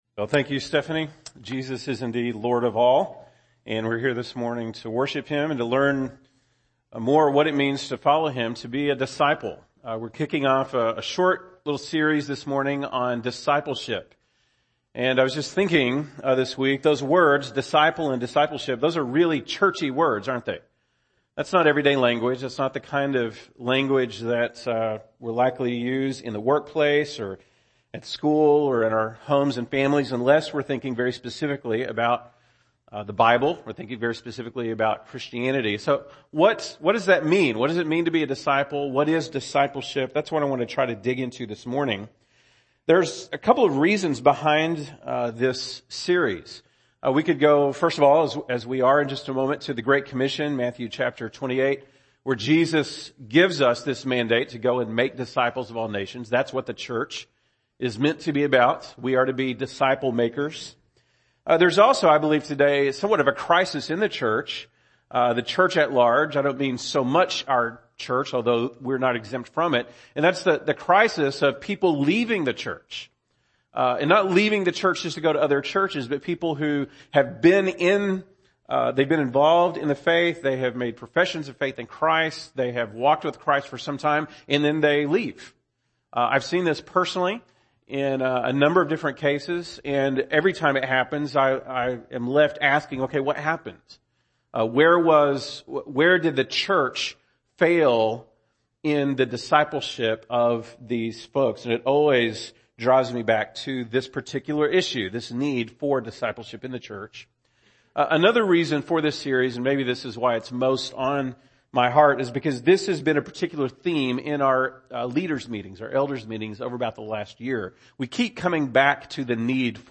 October 2, 2016 (Sunday Morning)